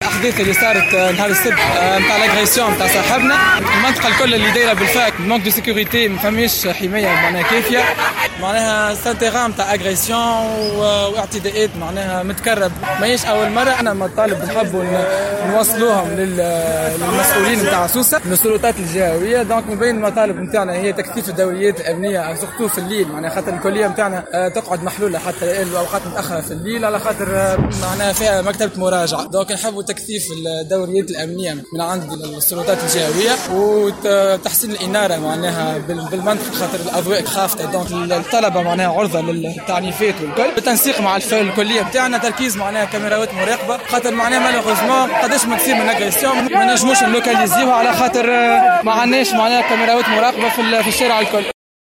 أحد الطلبة المحتجين تحميل المشاركة علي مقالات أخرى وطنية 21/08/2025 رئيسة الحكومة: 'تونس تعتز بانتمائها الافريقي..